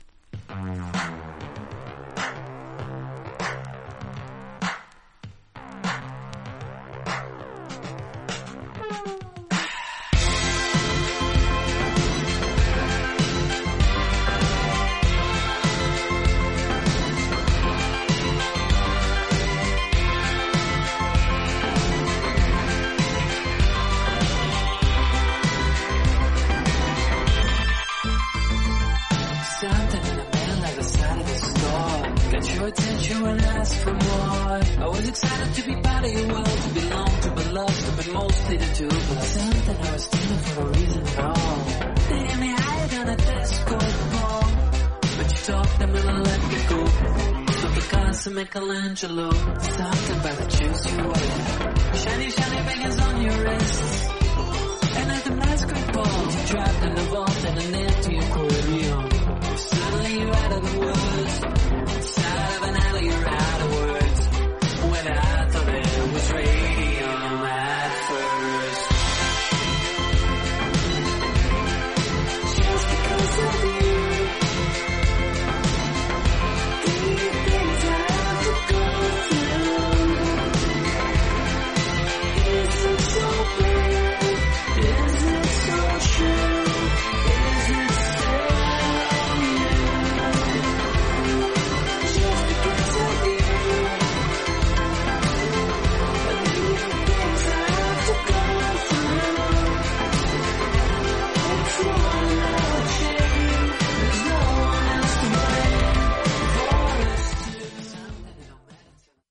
実際のレコードからのサンプル↓ 試聴はこちら： サンプル≪mp3≫